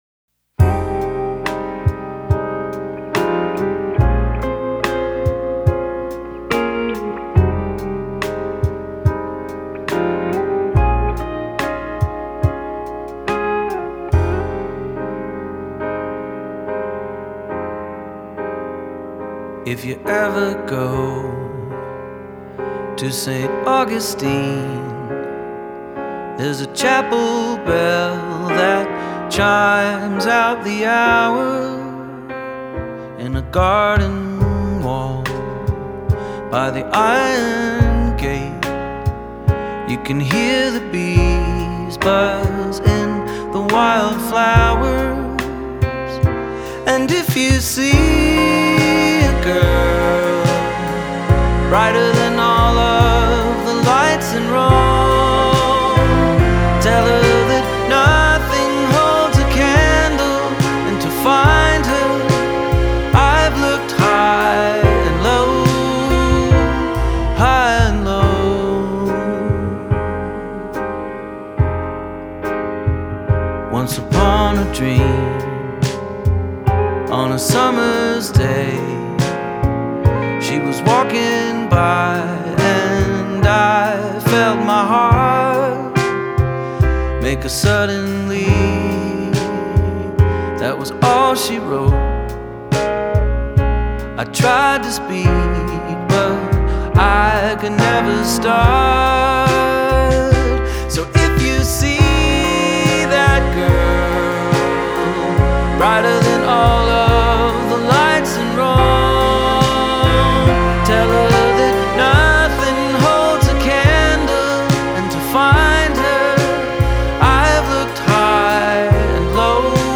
hits the bittersweet spot.